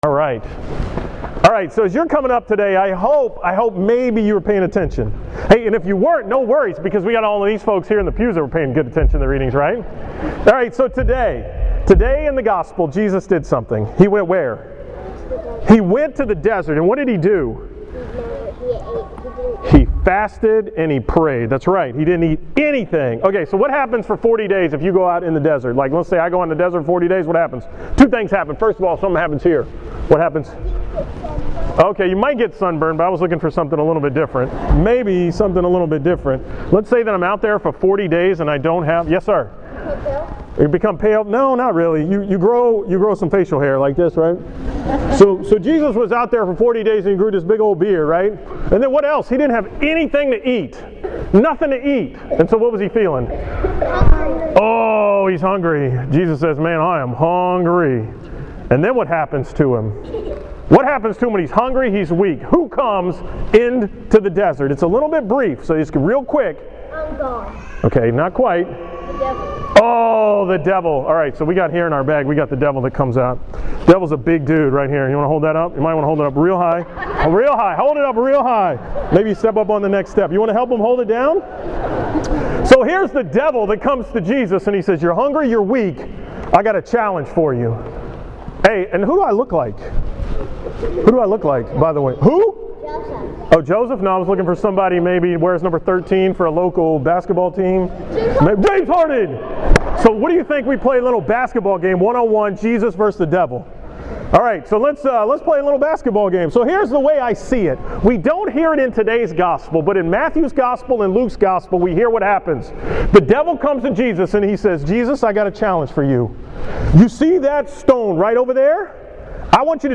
From the 9:30 am Mass at St. Maximilian Kolbe